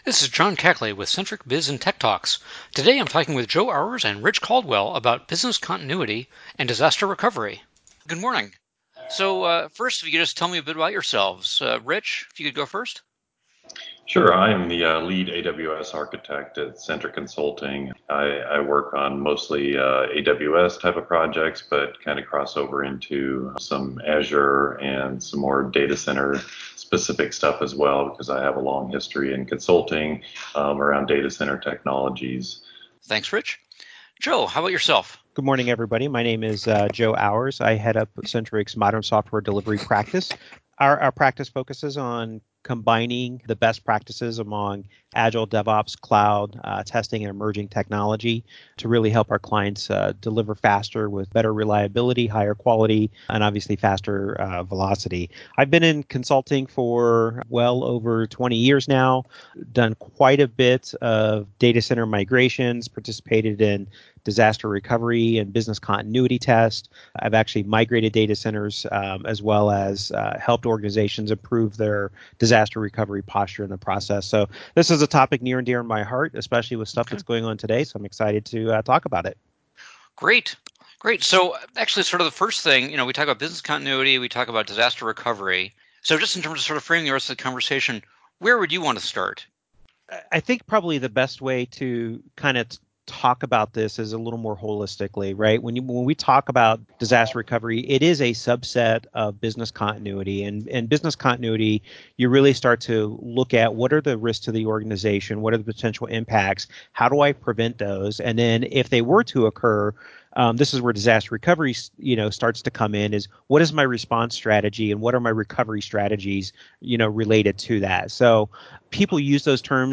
Podcast-Interview-23-BC-and-DR.mp3